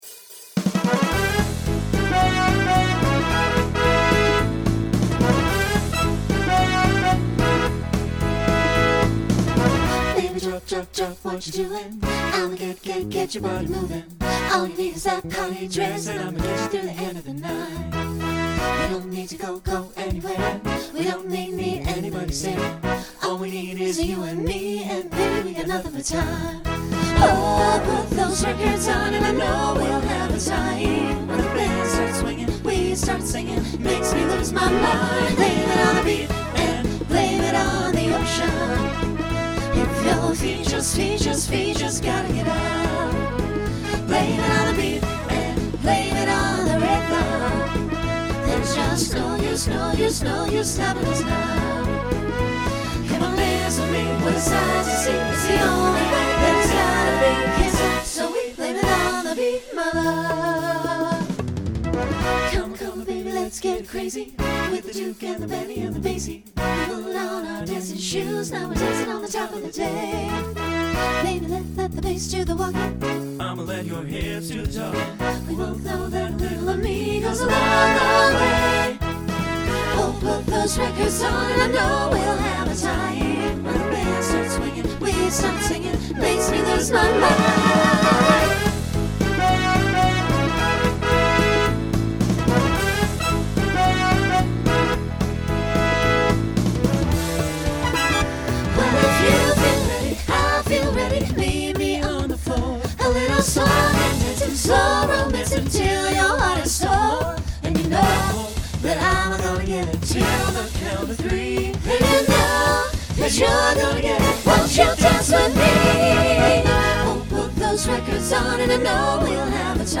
Genre Swing/Jazz Instrumental combo
Voicing SATB